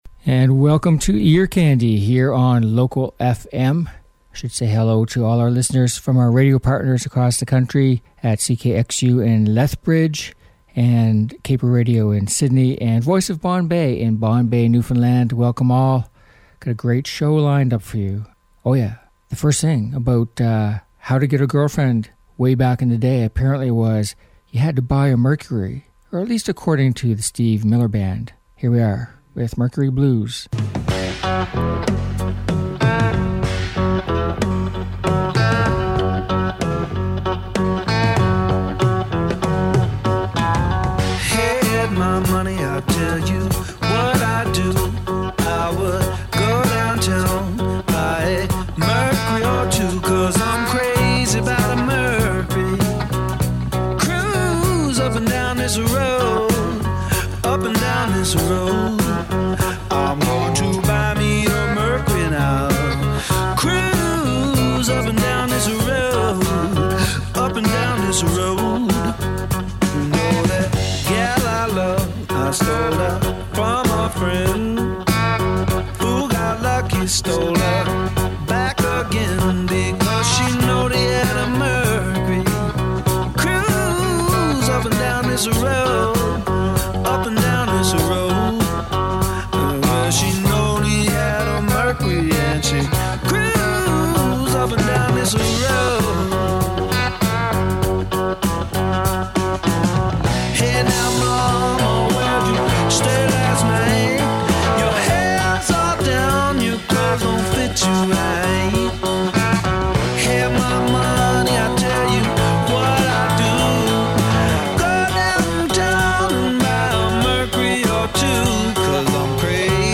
Uptempo Rock and Pop Songs